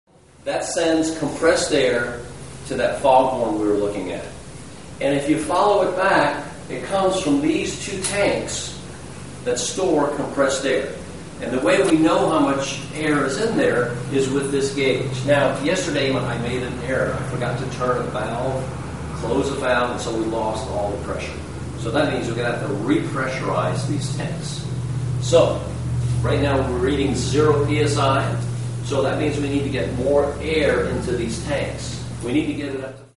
Audio file of the Fog House engine